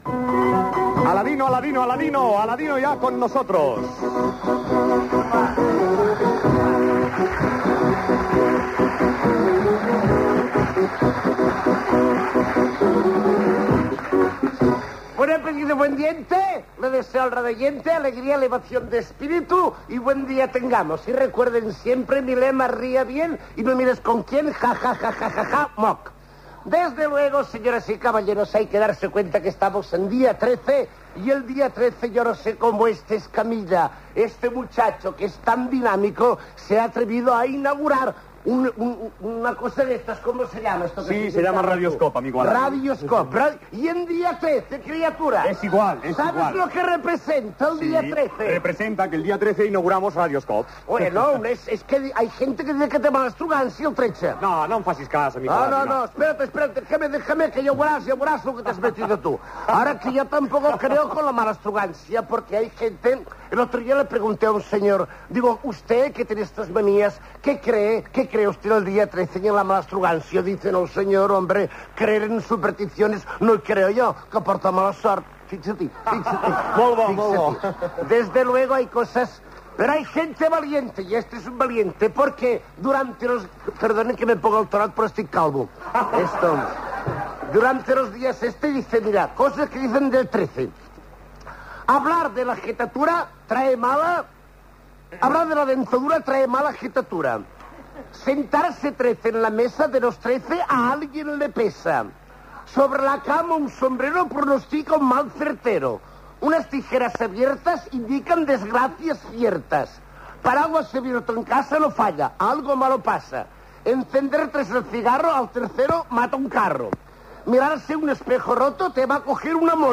Participació de l'humorista Alady (Carlos Saldaña), en el dia de l'estrena del programa: comenta coses relacionades amb el número 13 i explica alguns acudits
Entreteniment